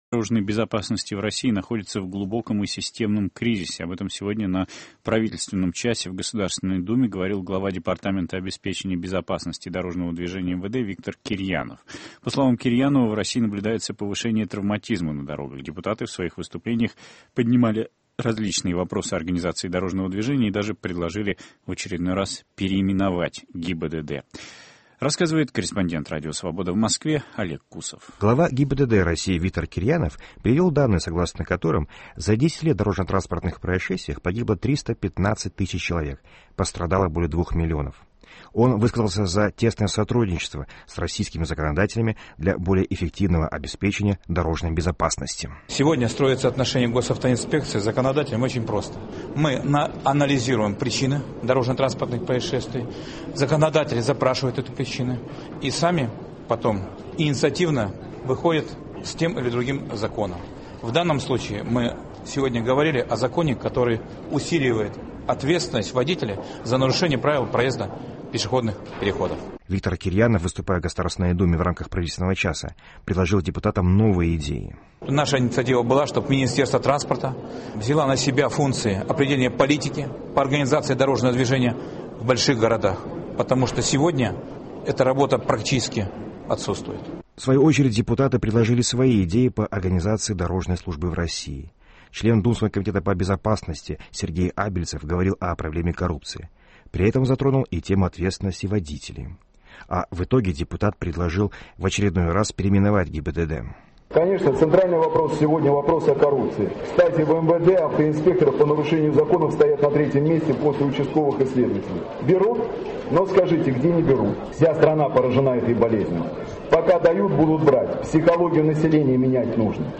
Глава ГИБДД России Виктор Кирьянов выступил на парламентском часе в Госдуме